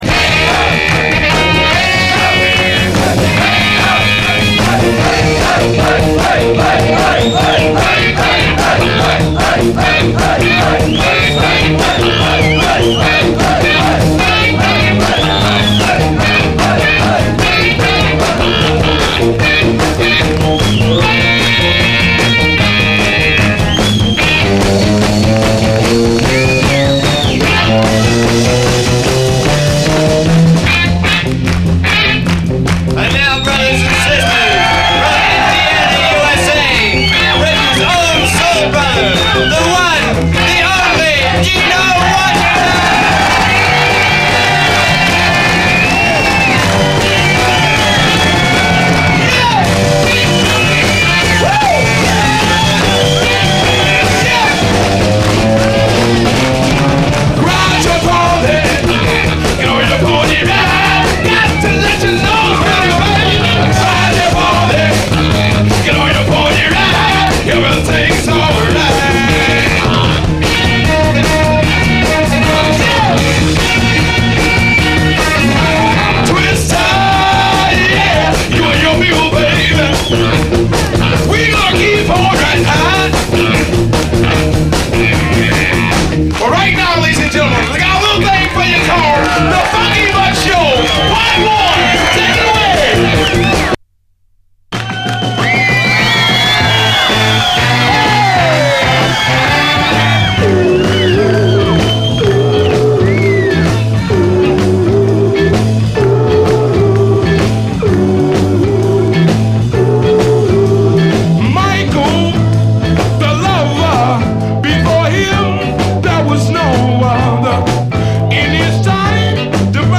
カナダ産のド・マイナー・スウィート・レゲエ＆インディー・モダン・ソウル！
エモーショナルかつソウルフルなビューティフル・チューンです！
ブギー・ファンク調のモダン・ソウル・ヴァージョンは、コレ同じ曲か？というくらいにインディー・ソウルな別の味わいです。